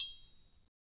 Triangle3-HitFM_v1_rr2_Sum.wav